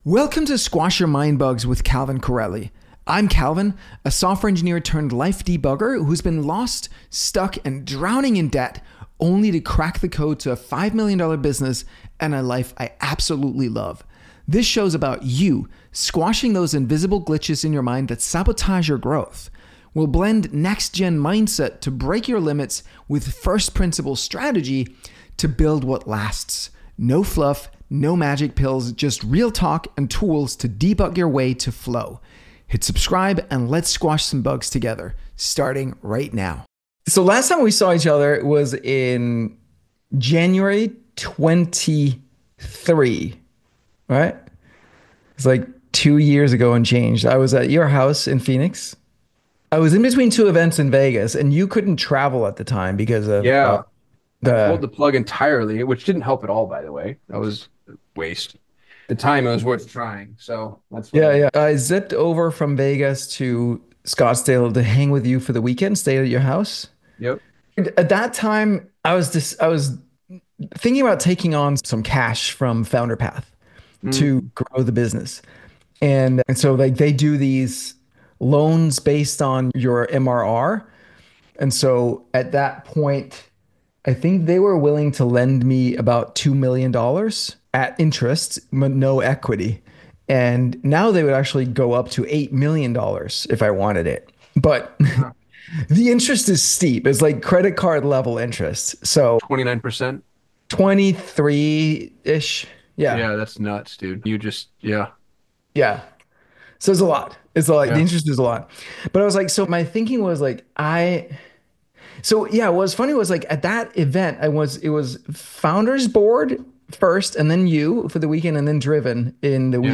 In this episode, I dive into a powerful conversation